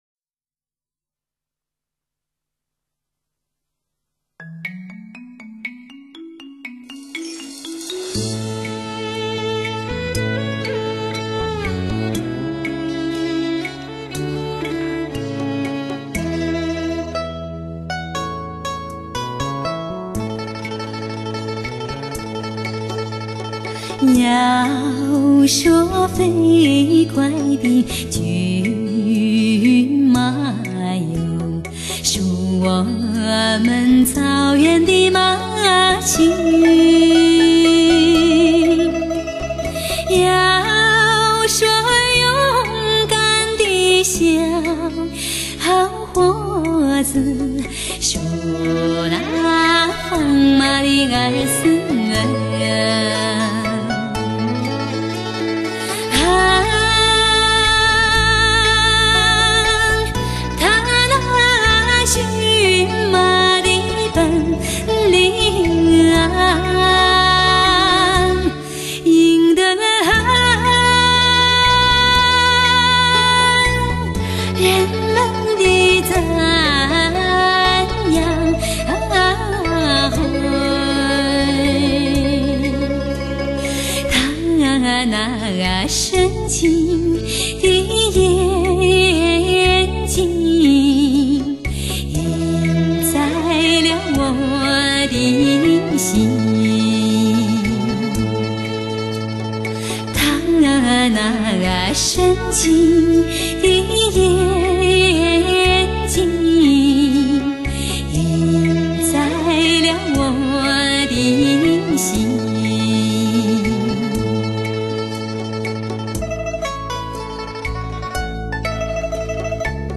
草原天籁 莺歌飞翔